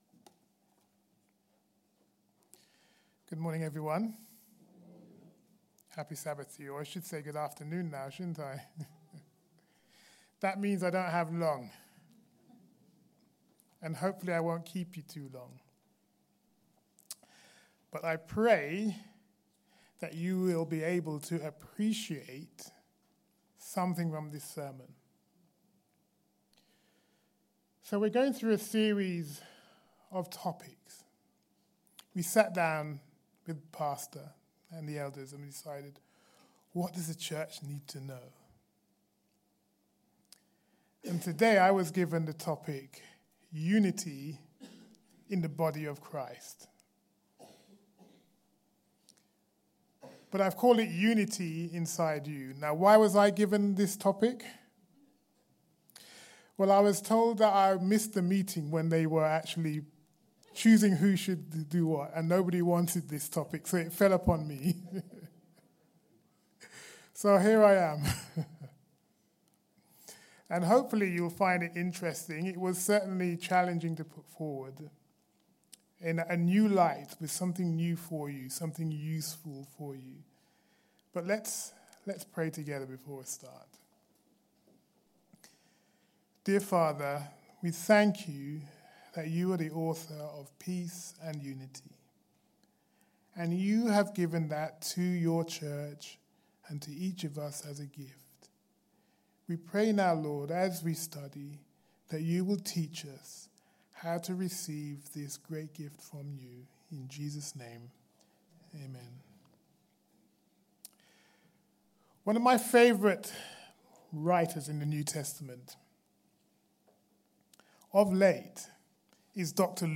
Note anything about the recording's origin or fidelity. Family Service Recordings